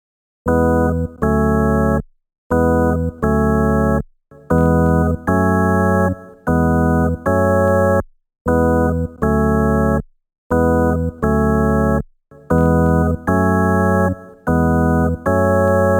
描述：听听甘迪亚港附近的啤酒花店
标签： 市场 海滩 商店 纪念品 强麦
声道立体声